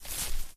FootstepGrass08.ogg